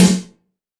SNARE 105.wav